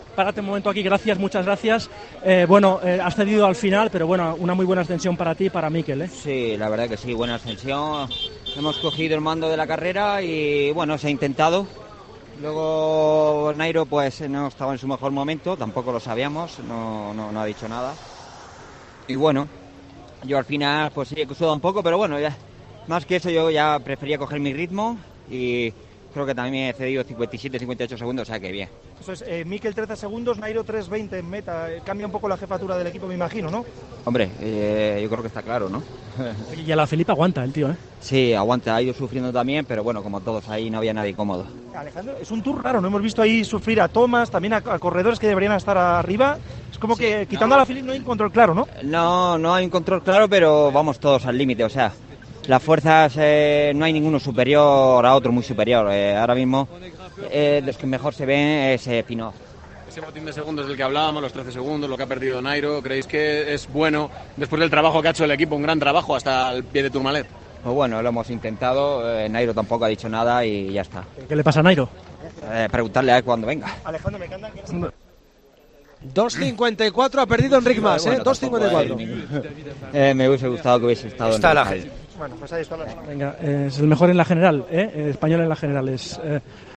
El ciclista del Movistar habló con los medios tras la etapa del Tourmalet.